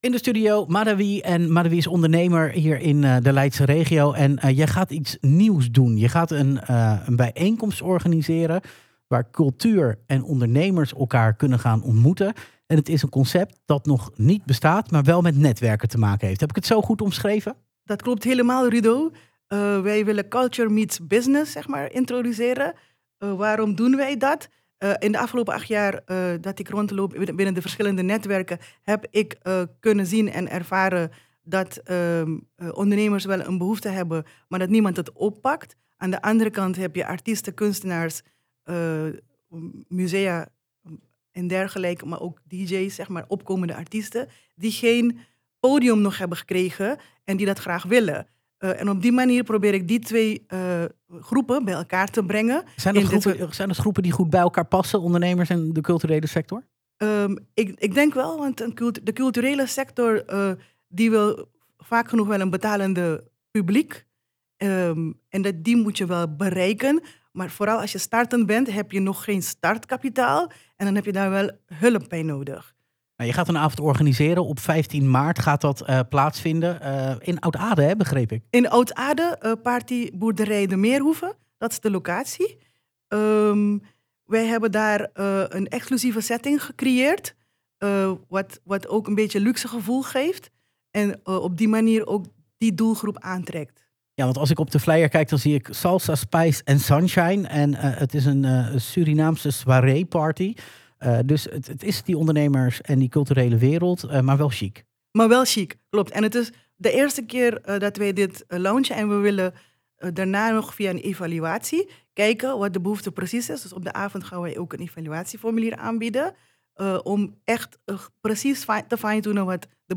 Interview-Royal-Soiree.mp3